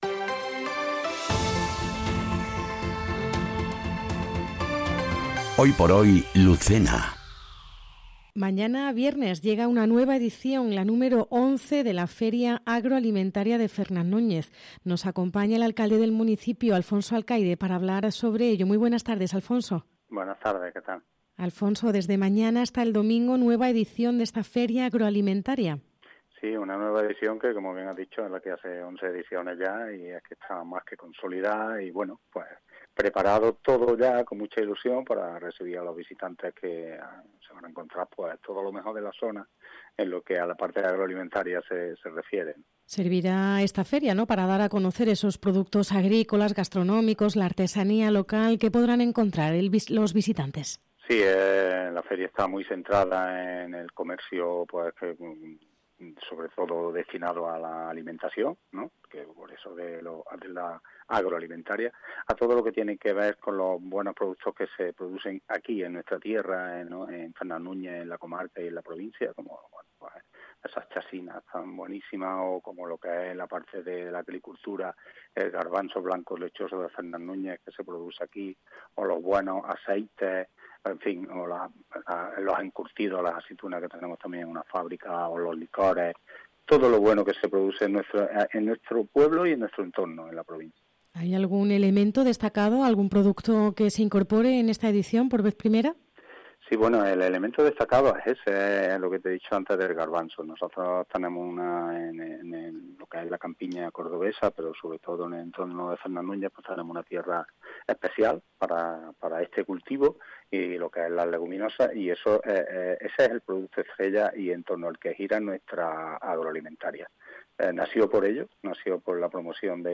ENTREVISTA | Feria Agroalimentaria de Fernán Núñez
Entrevista en Hoy por Hoy Andalucía Centro Lucena a Alfonso Alcaide, alcalde de Fernán Núñez, sobre la XI edición de la Feria Agroalimentaria